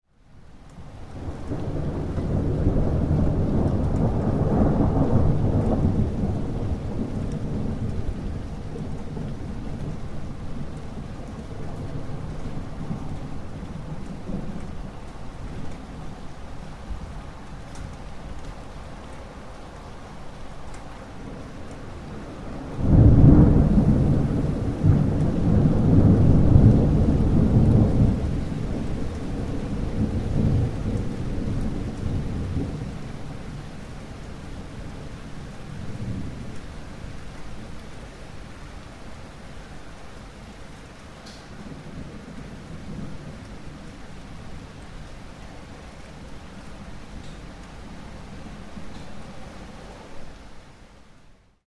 Thunderclap Sound Effect
Nature Sounds / Sound Effects / Thunderstorm Sounds
Thunderstorm-noise.mp3